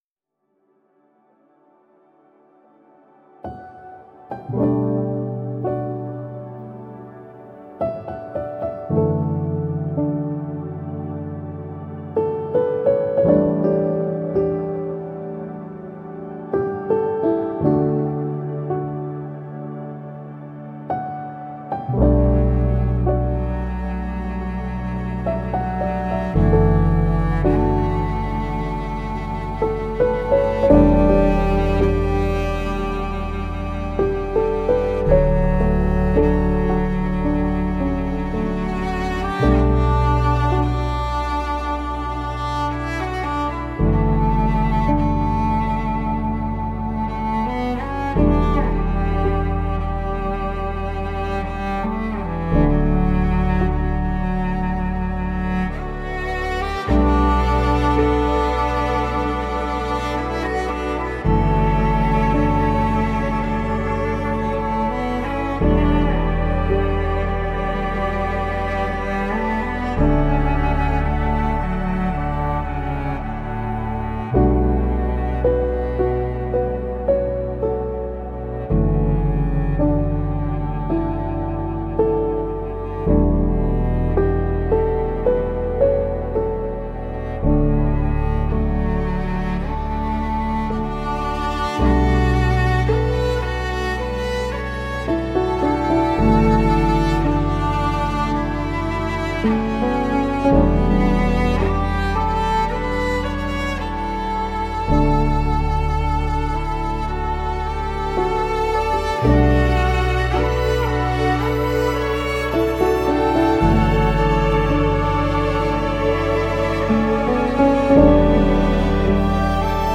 موسیقی بی کلام
Ambient